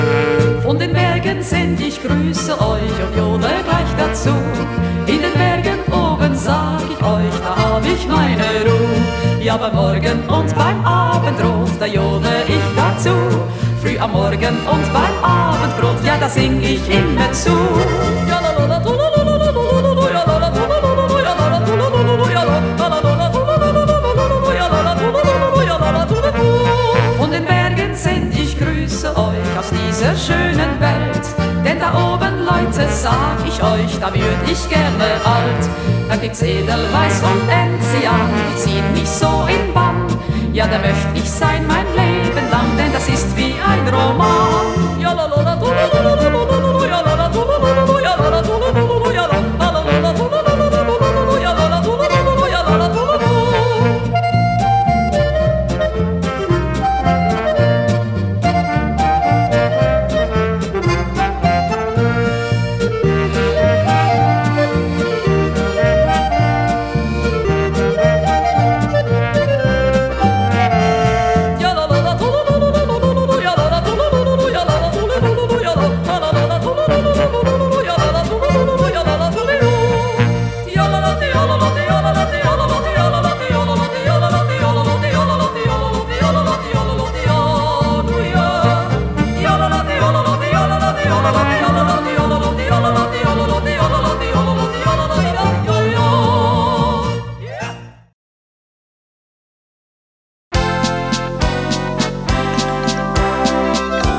Genre: Folk / Country / Retro / Yodel